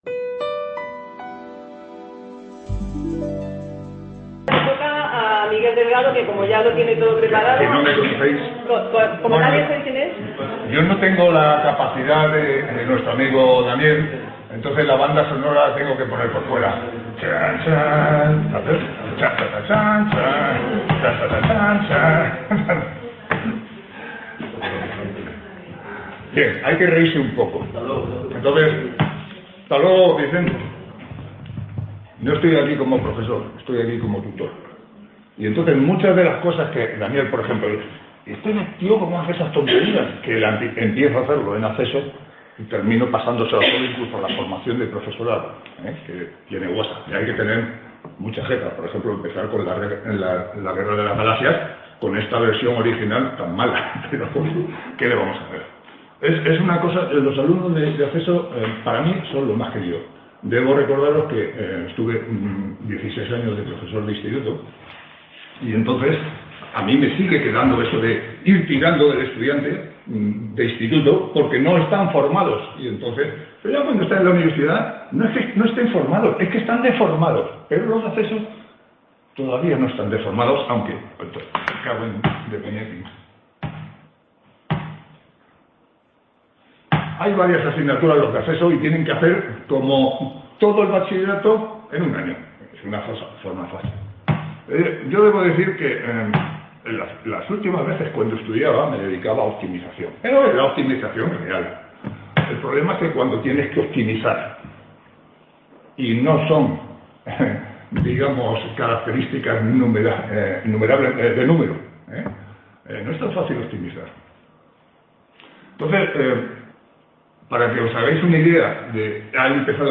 Ponencia S-3ª Una ingeniería didáctica: Una forma de…
Sextas Jornadas de Experiencias e Innovación Docente en Estadística y Matemáticas (eXIDO22)